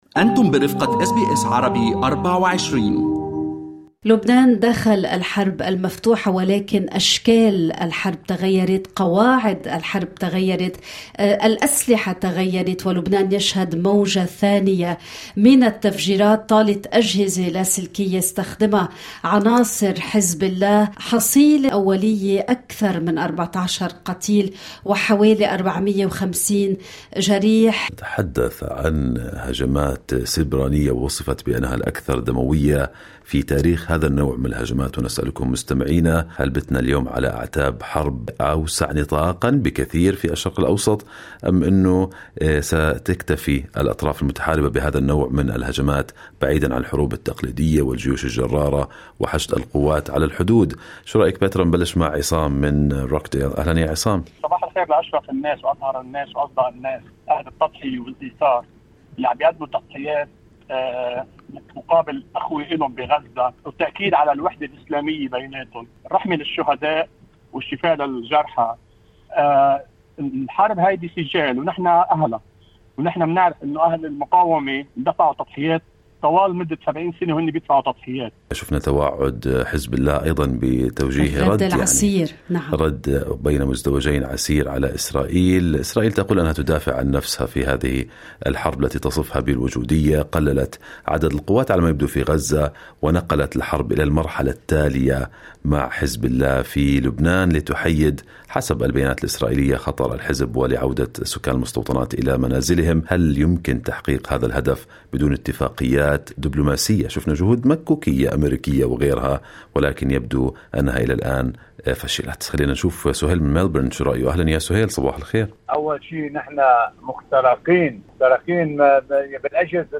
تشهد لبنان سلسلة جديدة من الانفجارات الاستثنائية، تستهدف هذه المرة أجهزة الاتصال اللاسلكية (الواكي توكي)، مما أسفر عن مقتل 20 شخصًا وإصابة أكثر من 450 آخرين في مدن مختلفة. سألنا الجالية العربية عما حدث ضمن ففقرة الحوار المباشر في Good Morning AUstralia